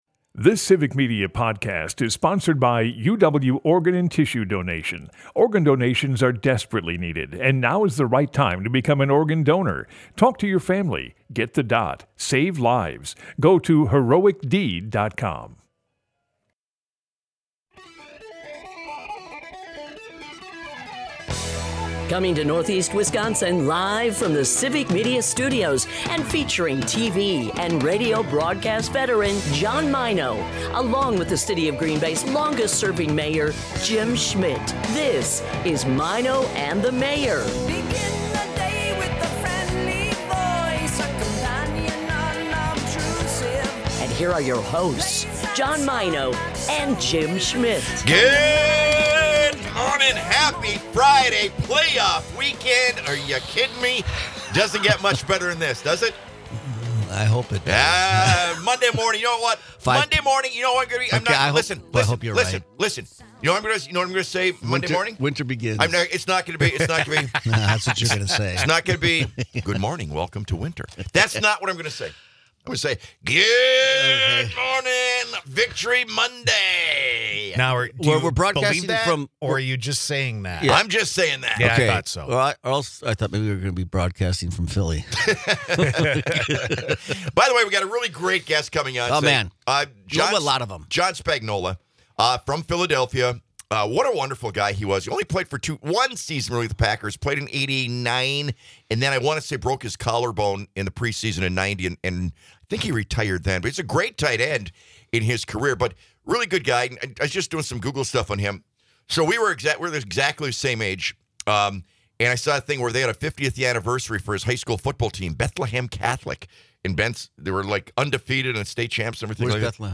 Broadcasts live 6 - 9am in Oshkosh, Appleton, Green Bay and surrounding areas.